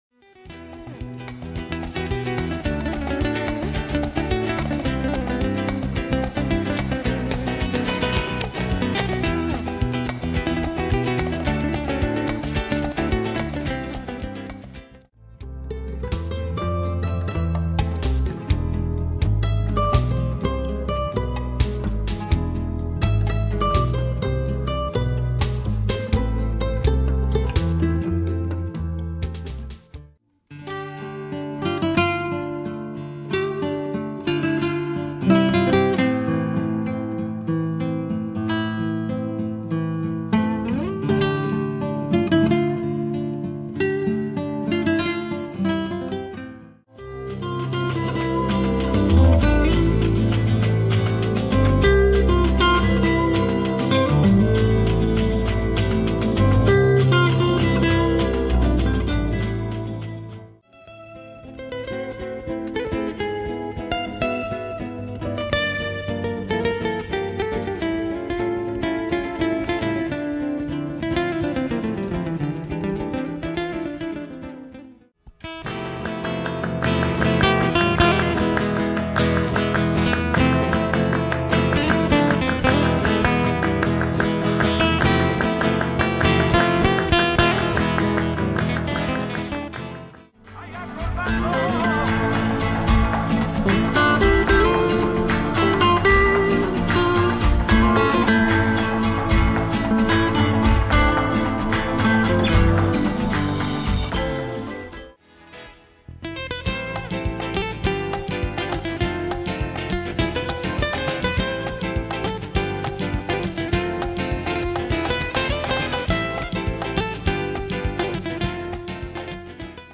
Flamenco guitar and castanets